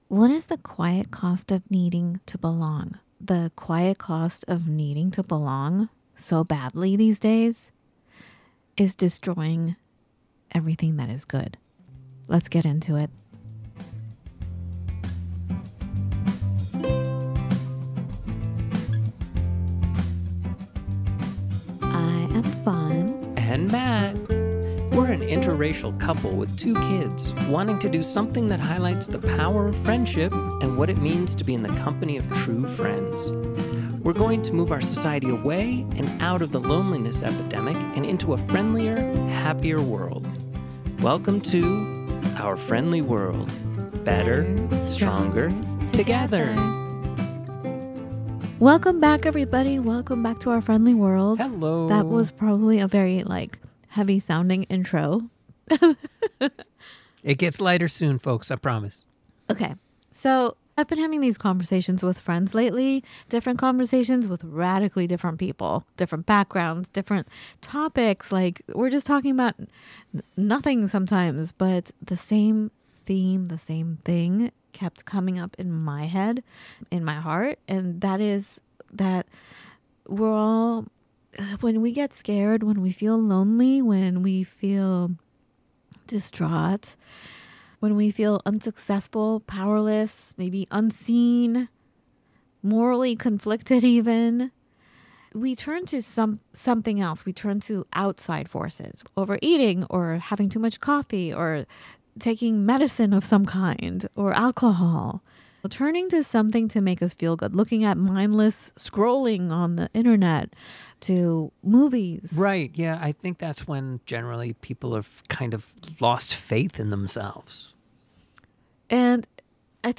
From imposter syndrome and victimhood to freedom, individuality, and living with uncertainty, this conversation invites listeners to reclaim their inner authority.